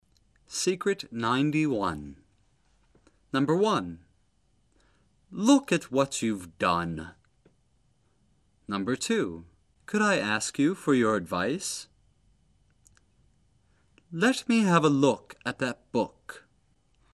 李阳美语发音秘诀MP3之秘诀91:后元音的发音技巧
在李阳疯狂英语中叫做“后缩舌、双唇收圆、短促略突”音。
美音：［l?k  At    hwBt  juv    dQn］
美音：［k?d   aI   Ask   fRr   jur   Ed5vaIs］